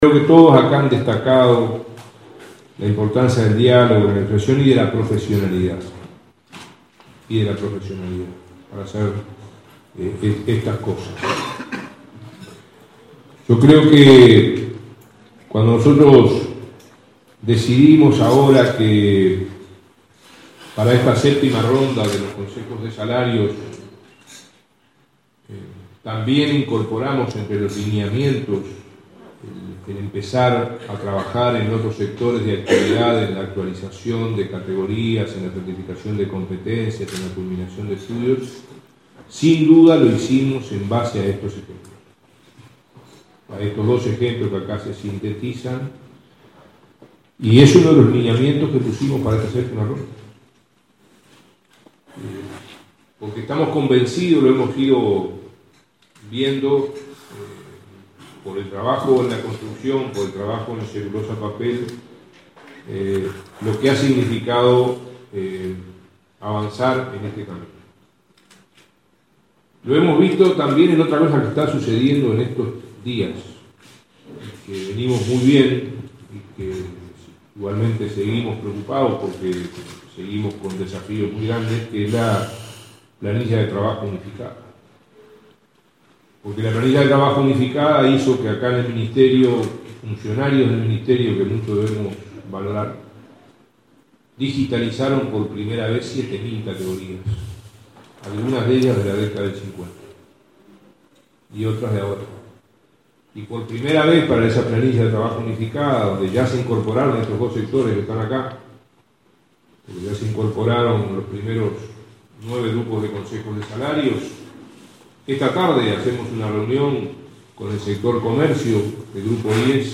Durante la presentación de una publicación sobre desarrollo de competencias sectoriales y diálogo social en Uruguay, el ministro de Trabajo, Ernesto Murro, destacó los avances de Uruguay en certificación de competencias, culminación de ciclos educativos de trabajadores y formación dual. También valoró la incorporación de 7.000 categorías en la nueva planilla de trabajo unificada.